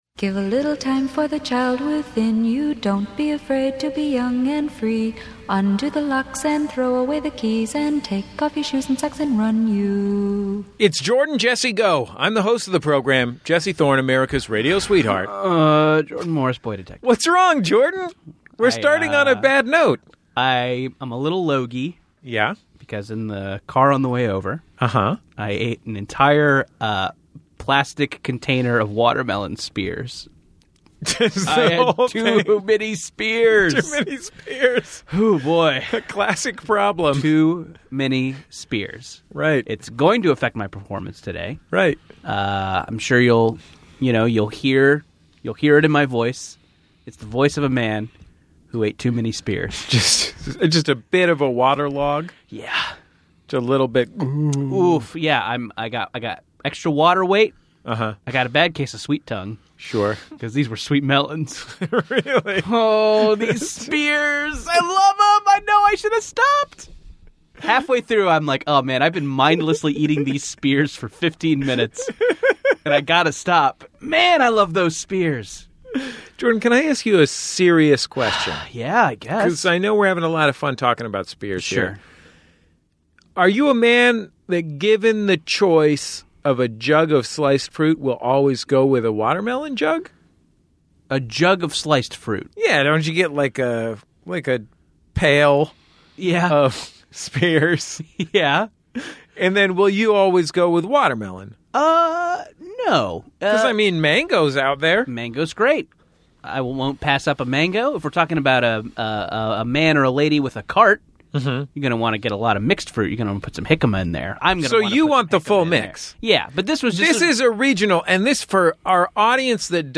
Society & Culture, Comedy, Tv & Film